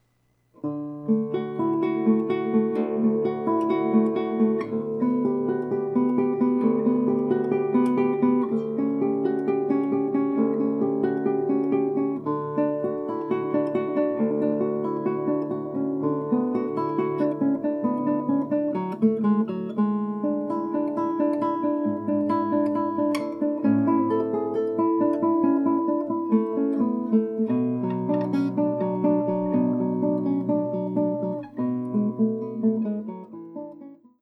TASCAM DR-22WL로 기타 연주 녹음하기
○ Wav, Stereo, 44.1Khz, Speaker On, Limiter Off, Peak Off, No Reverb, Input Jog 볼륨 75, 헤드폰 볼륨 20, 장면 선택 다이얼은 ‘기타 모드’.
○ 집음 각도는 위에서 아래쪽으로 40도 정도, 30cm 이격, 기타 울림통~Neck 연접점 지향.
○ Audacity MIC 볼륨 0.9, Noise Reduction 처리.
prelude_test_noise-reduction.wav